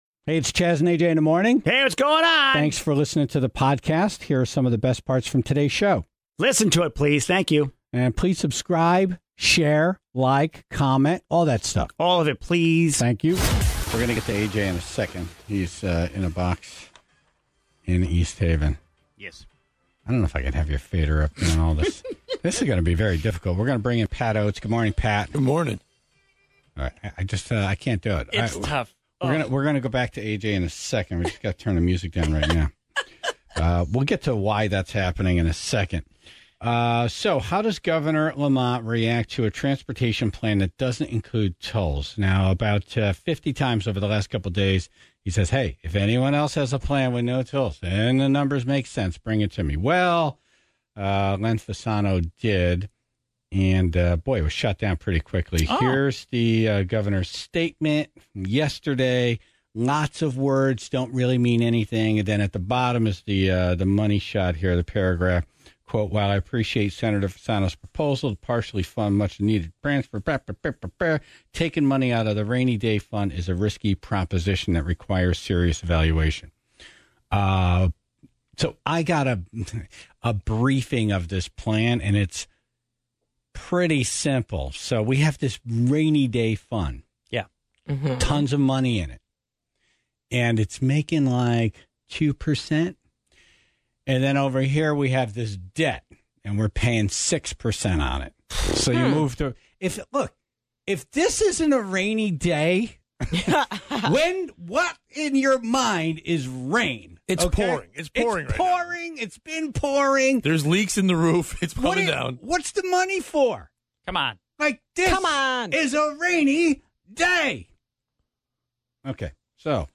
live from the box